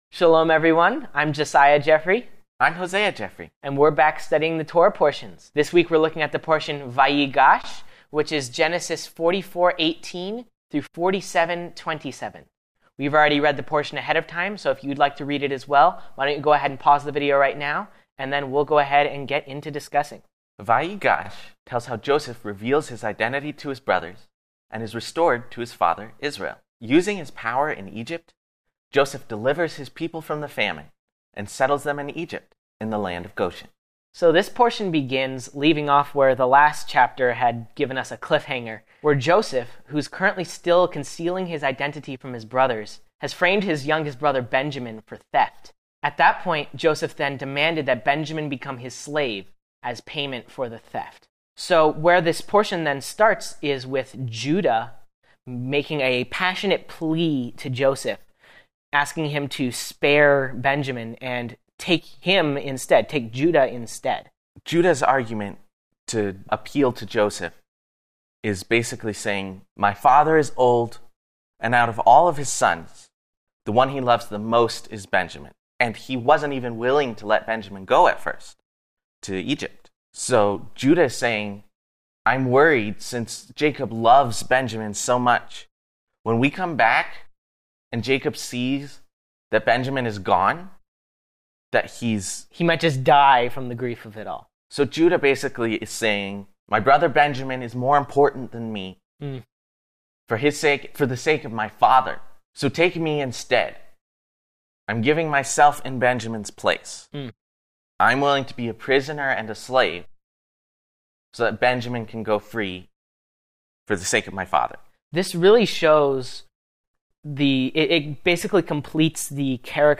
In this week’s Messianic Jewish Bible study,